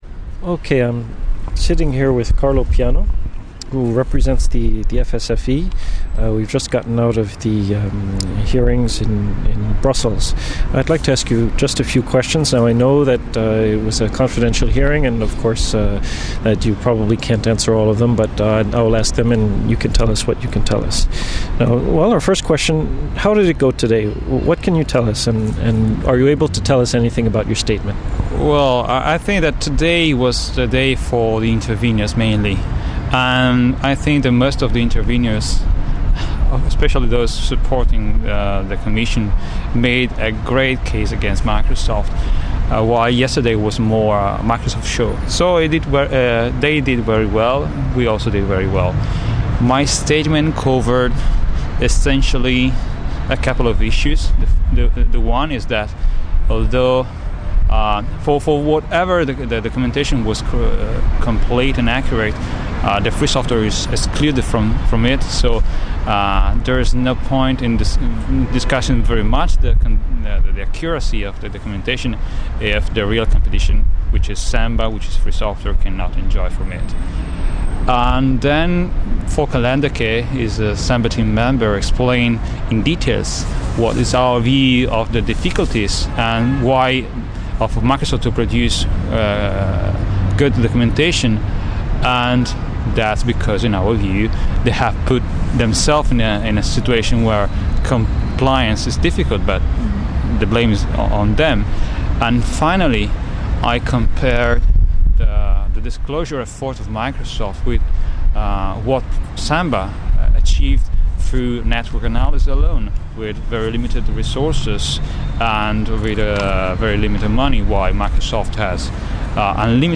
A short interview that was taken right after the from on a historical day in the Microsoft antitrust penalty hearings in Brussels, 30 March 2006.